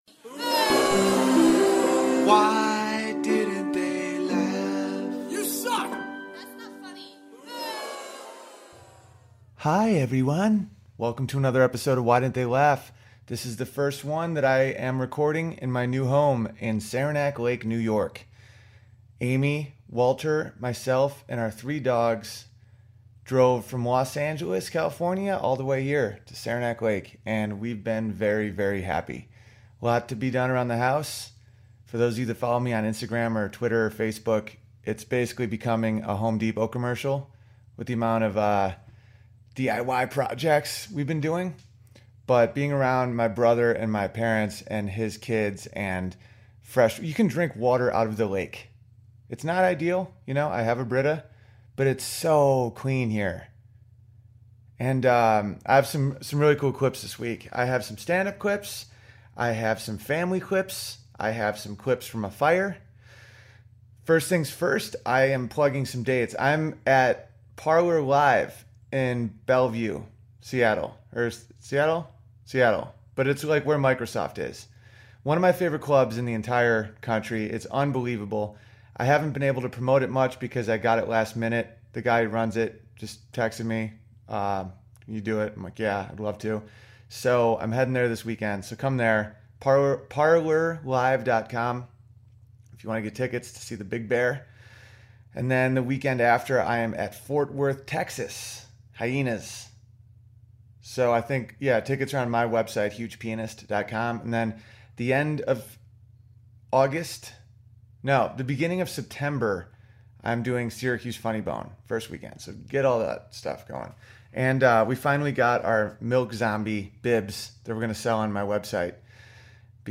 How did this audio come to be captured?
First episode recorded at our new home in Saranac Lake, NY. My dad has turned a corner in his life so I play a little audio from him. I show you examples of how much of an asshole I am compared to my wife, and I show with stand up clips what the main factor was for moving away from Los Angeles.